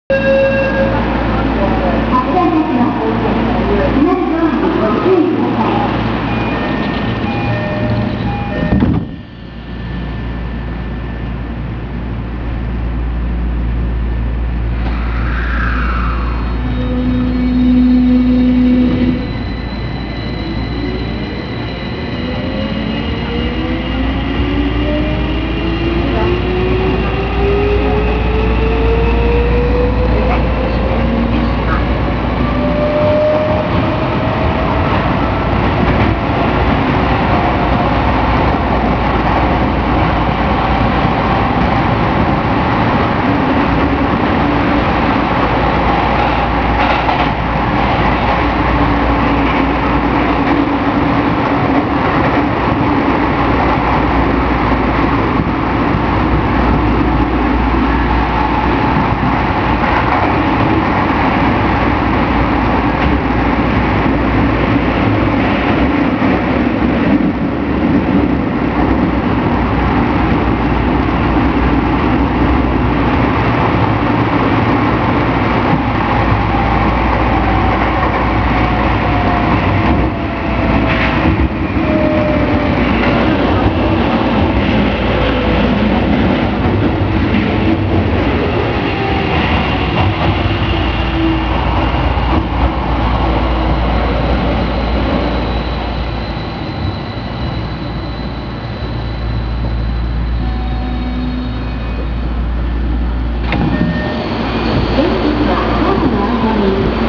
・16000系2次車まで走行音
【千代田線】根津〜湯島（1分47秒：582KB）
今までの車両に無かった走行音が目立っています。